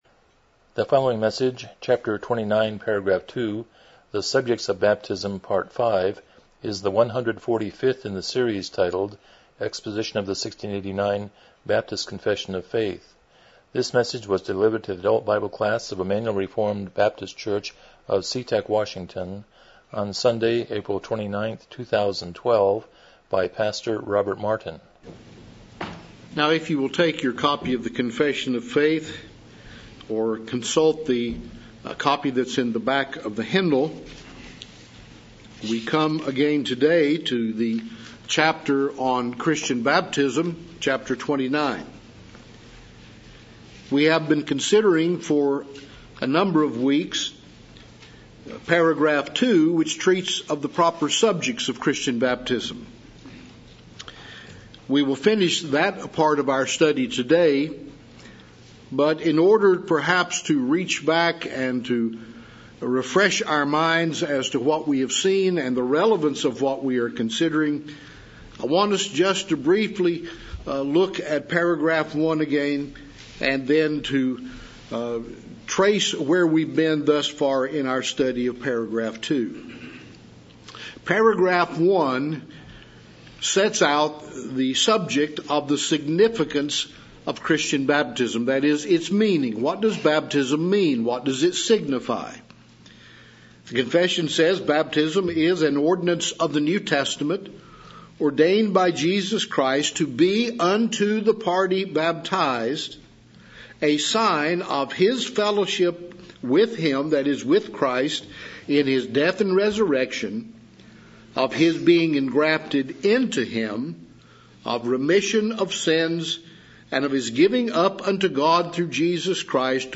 1689 Confession of Faith Service Type: Sunday School « 29 James 5:16b-18 168 Romans 16:20b